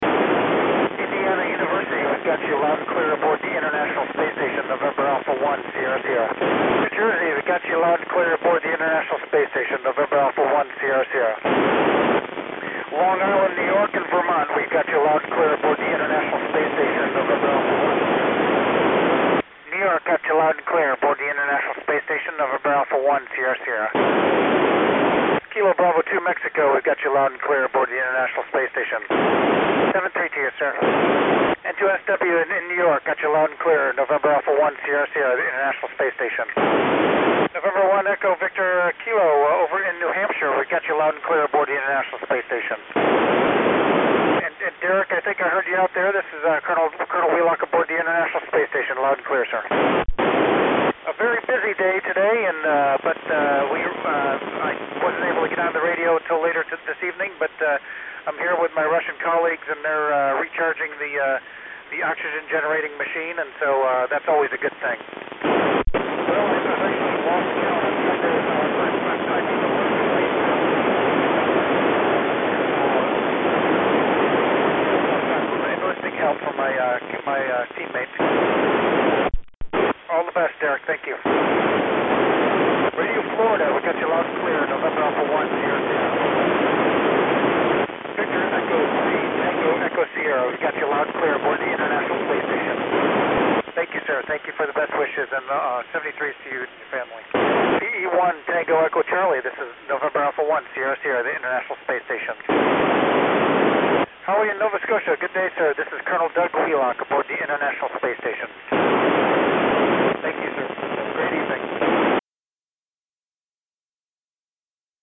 NA1SS RANDOM CONTACTS: July 12 2235Z